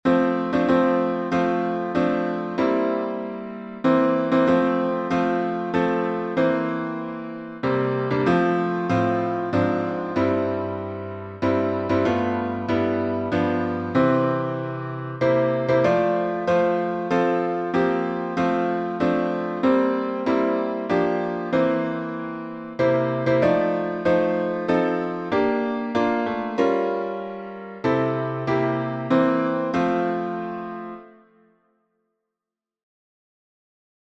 #1045: There's a Song in the Air — F Major | Mobile Hymns